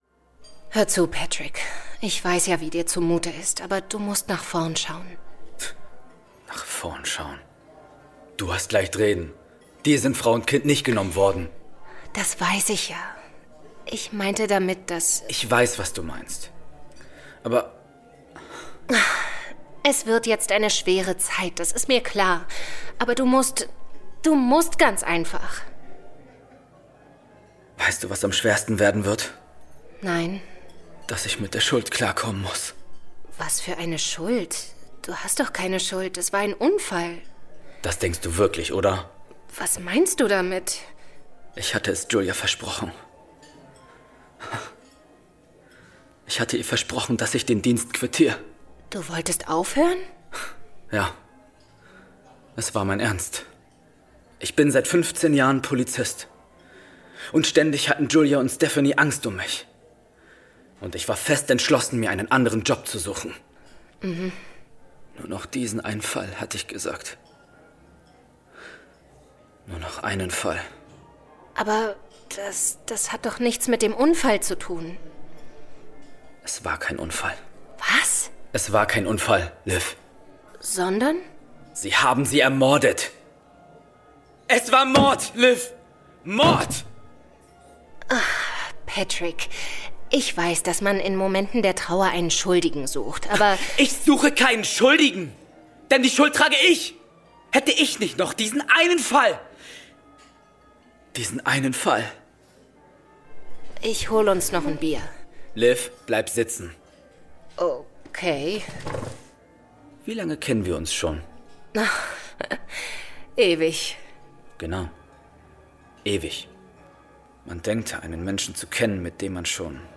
Die letzte Schuld Hörspiel Melancholisch Ausrangierter Polizist ohne Lebenswillen begleicht eine letzte Schuld in einer Bar. https